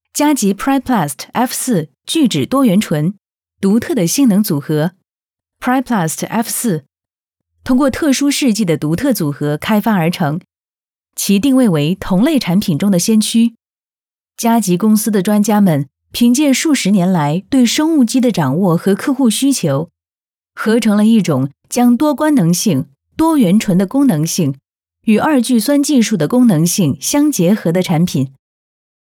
Priplast F4 – IFF – Locução em chinês mandarin para vídeo corporativo